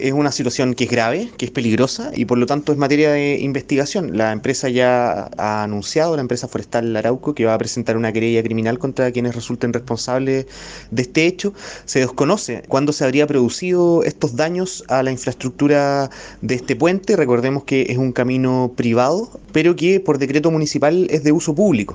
Al respecto, el seremi de Gobierno, Juan Guerra, confirmó que la empresa presentará una querella por este hecho.